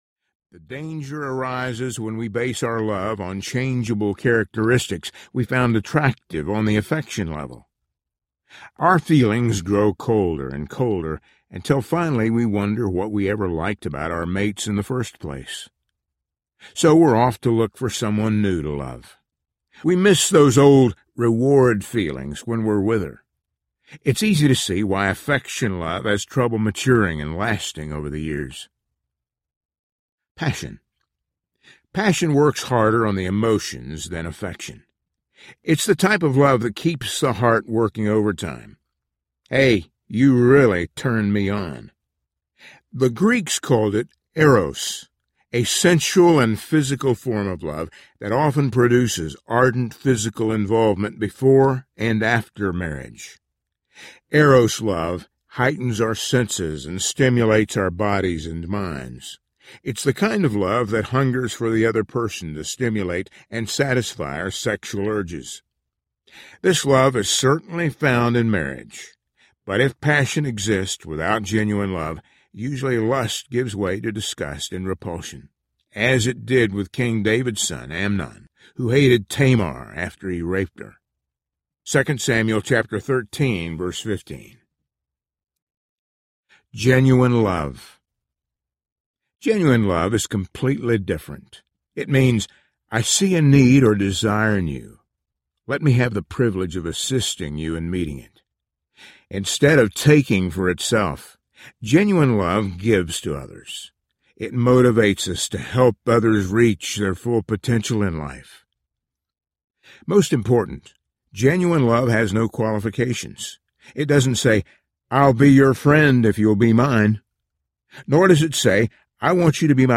If Only He Knew Audiobook
5.8 Hrs. – Unabridged